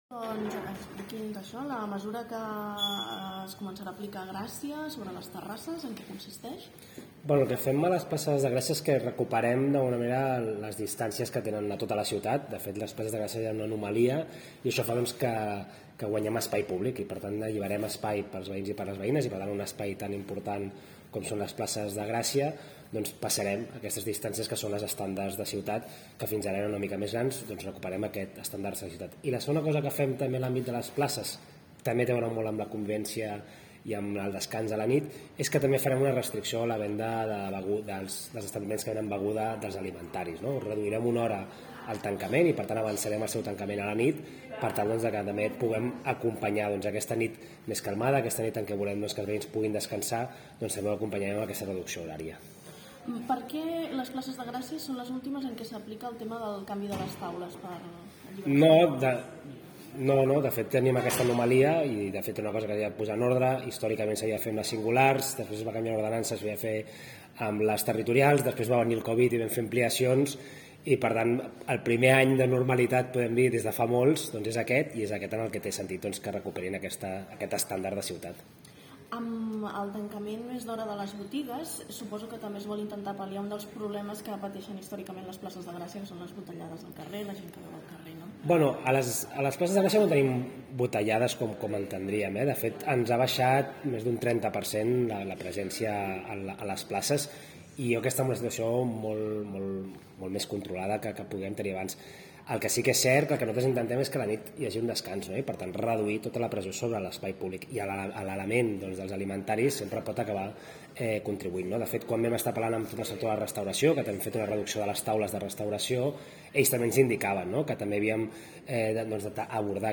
Declaracions d’Eloi Badia (MP3)